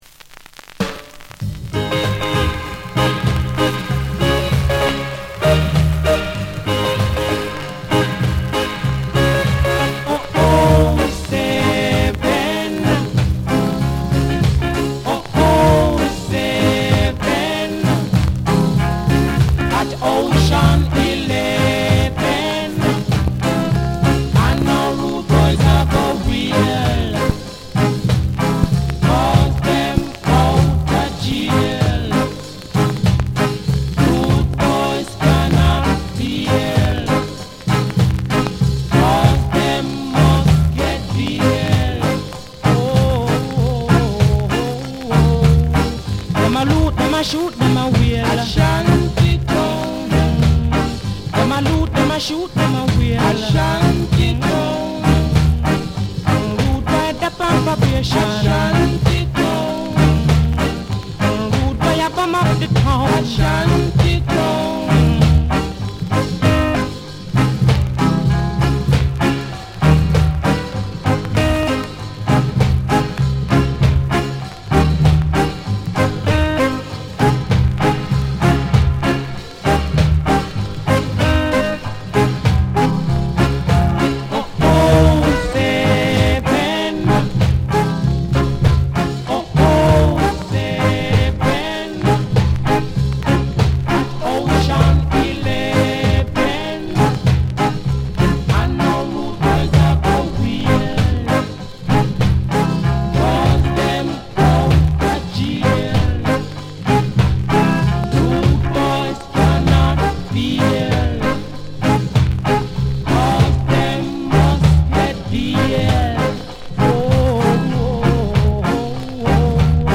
* 永遠のルード・ボーイアンセムとキラーなマカロニウエスタンロック・ステディ・インスト。